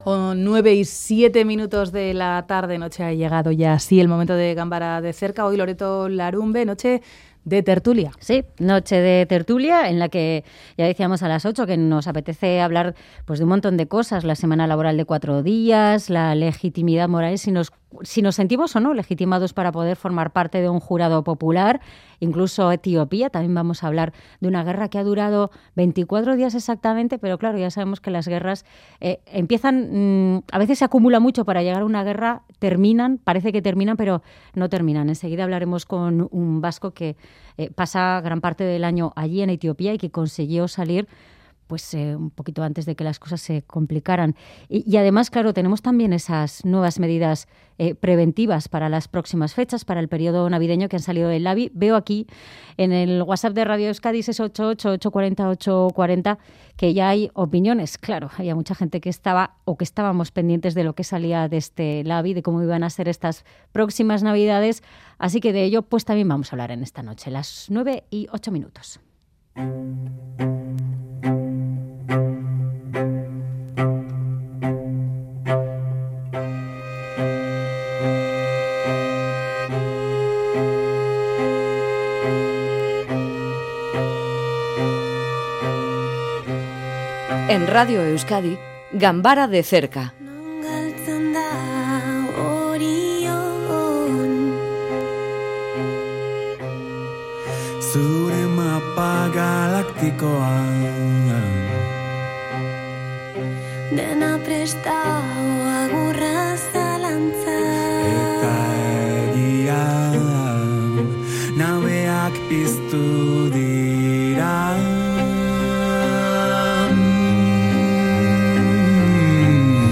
conversa en Ganbara sobre la guerra que ha enfrentado al gobierno etíope con el regional de Tigray